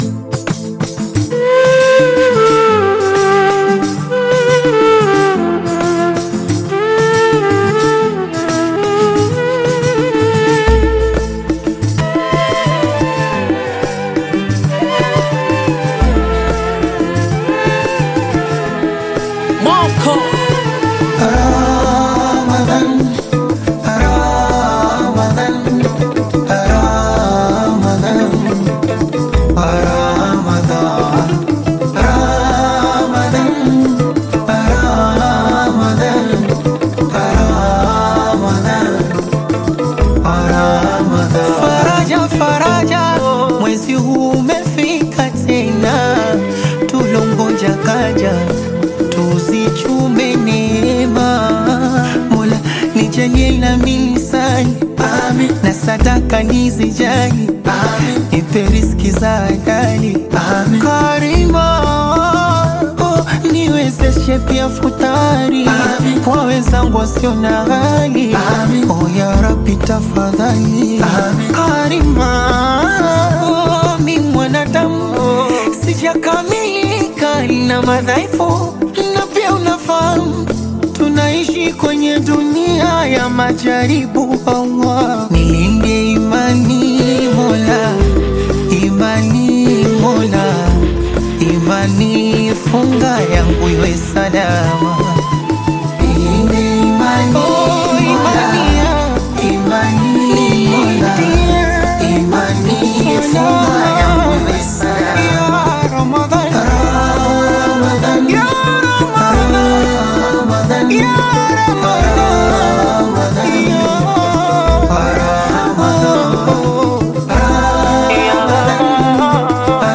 Qaswida music track
Qaswida song